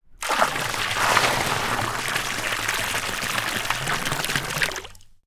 Water_56.wav